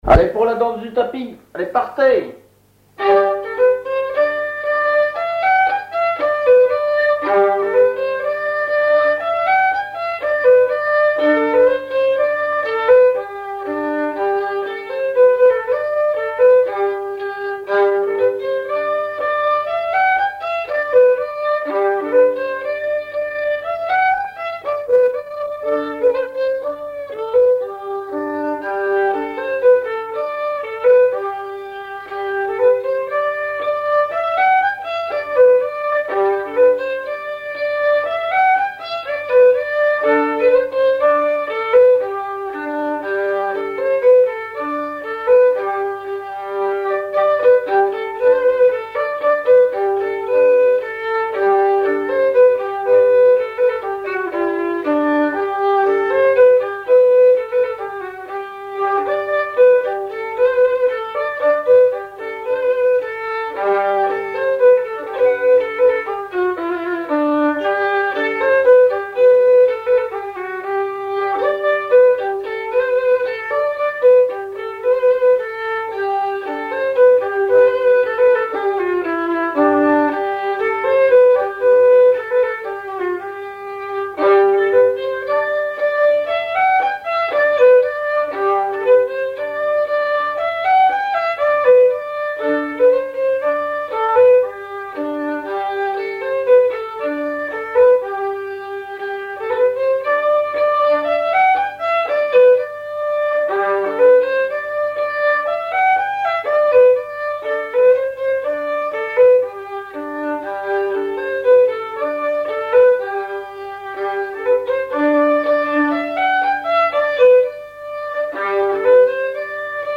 Rondes à baisers et à mariages fictifs
danse-jeu : danse du tapis
Pièce musicale inédite